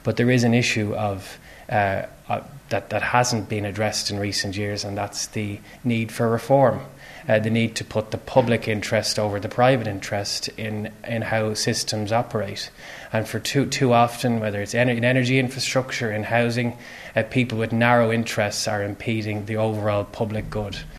Public Expenditure Minister Jack Chambers, says the ‘stop-start’ nature of projects in Ireland has gone on for far too long: